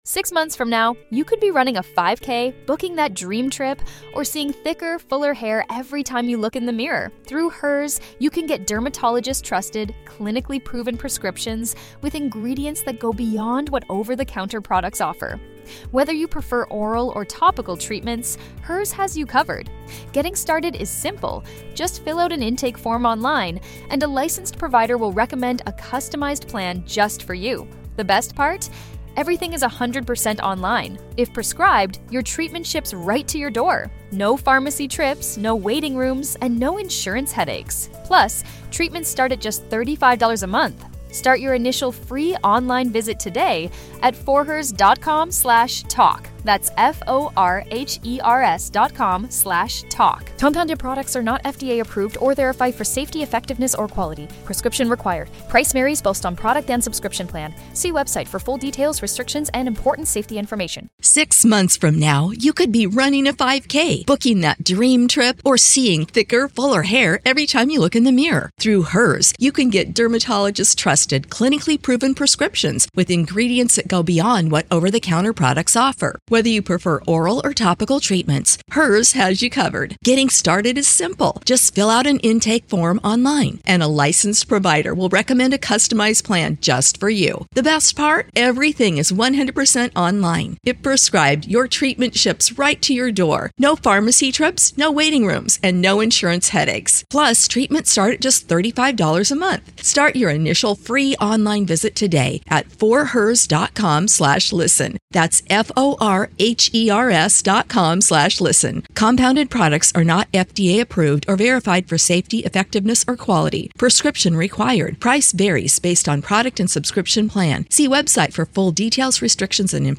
ASMR El perro de raza y el perro adoptado ASMR en Español